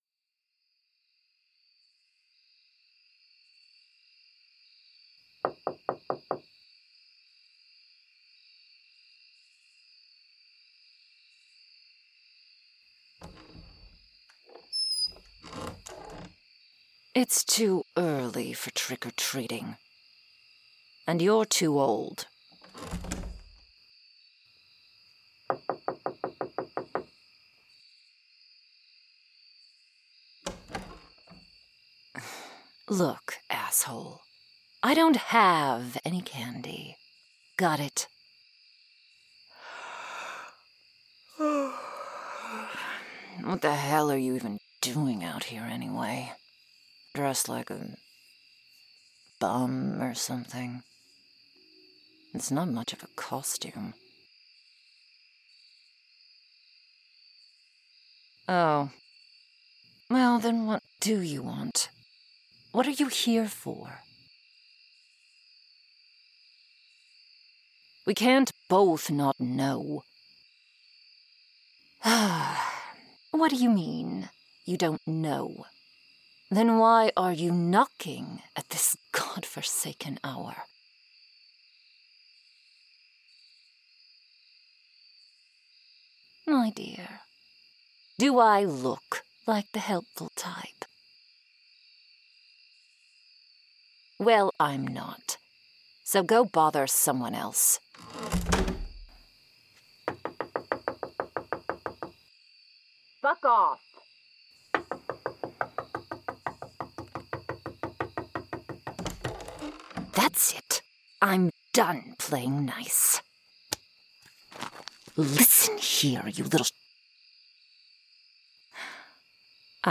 I hope you enjoy today's (belated!!) early upload, a witchy transformation roleplay, Becoming the Witch's Familiar!